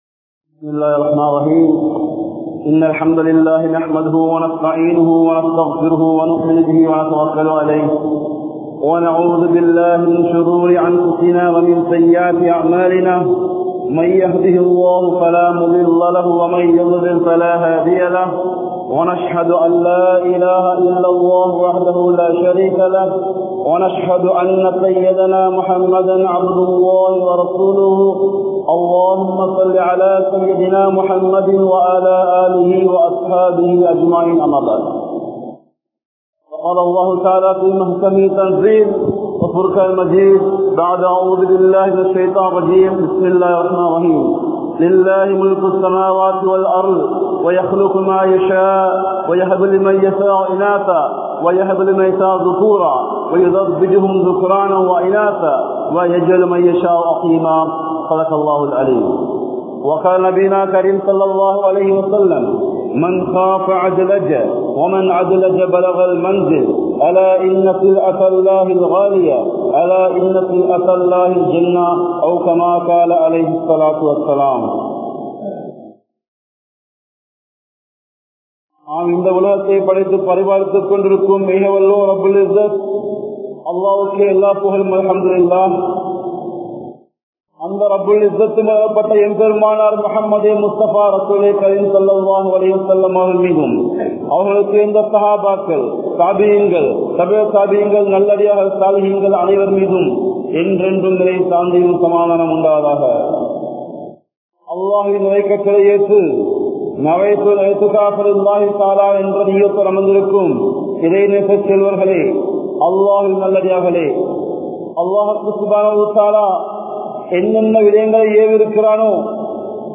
Vaalvil Ean Nimmathi Illai? (வாழ்வில் ஏன் நிம்மதி இல்லை?) | Audio Bayans | All Ceylon Muslim Youth Community | Addalaichenai